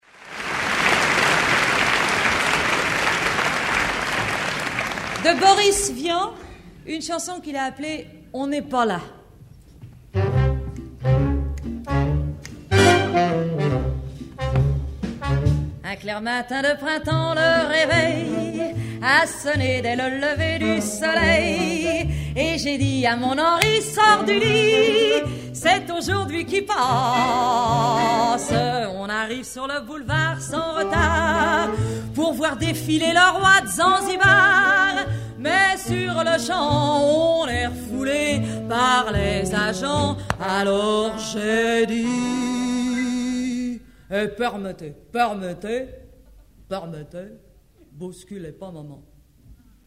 accordéon
Chante à l'Olympia Bruno Coquatrix